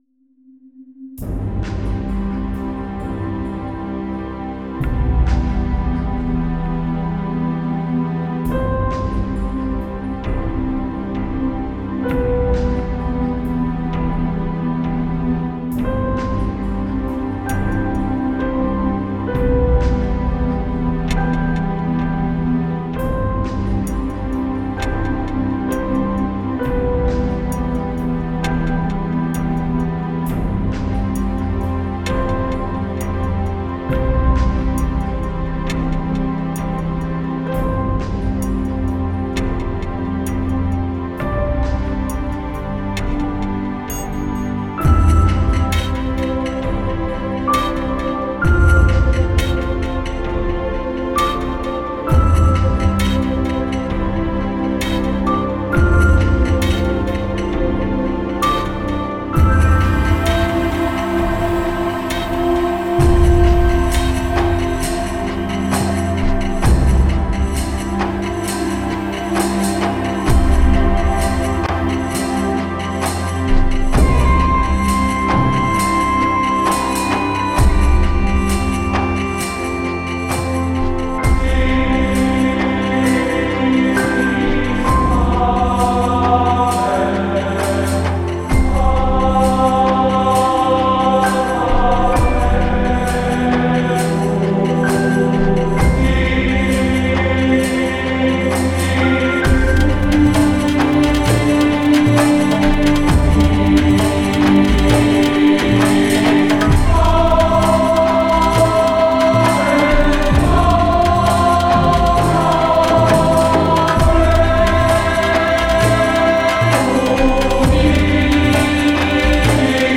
Genere: Instrumental.